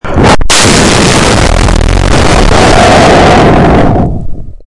Download Extremely Loud sound effect for free.
Extremely Loud